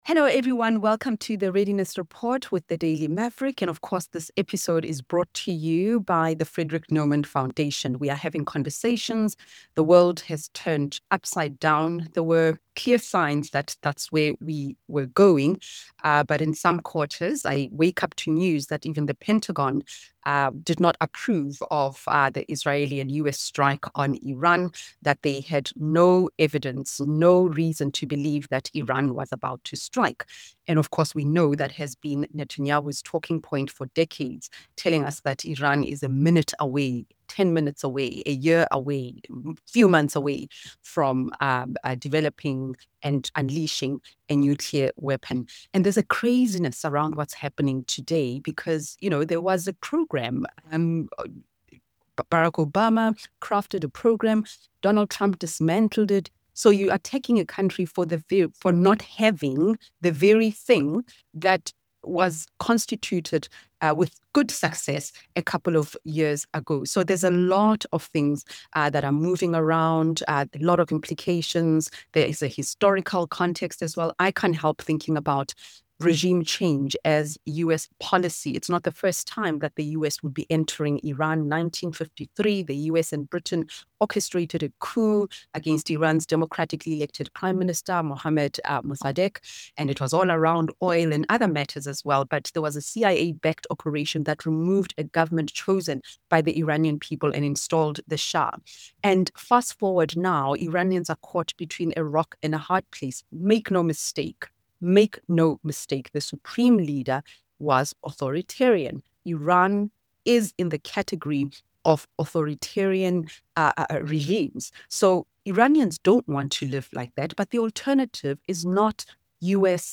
Journalist and political analyst